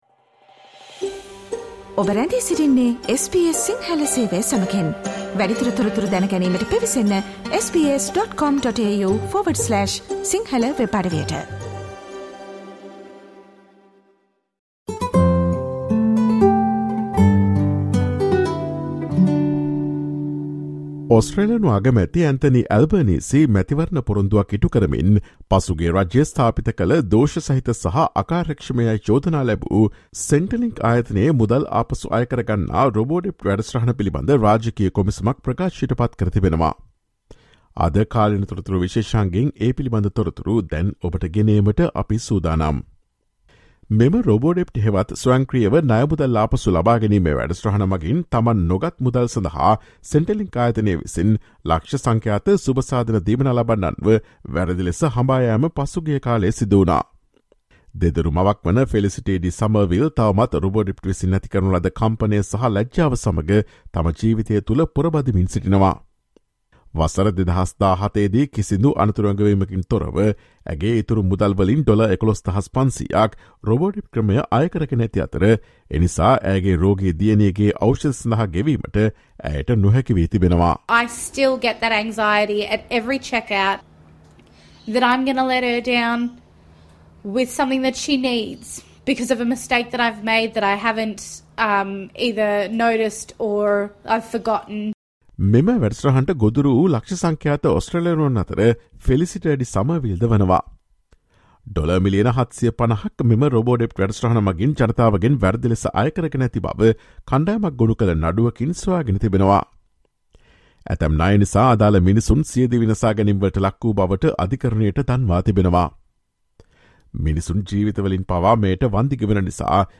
Prime Minister Anthony Albanese has announced a Royal Commission into the previous government's so-called 'Robodebt' scheme, fulfilling an election promise. Listen to the SBS Sinhala Radio's current affairs feature broadcast on Friday 26 August.